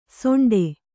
♪ soṇḍe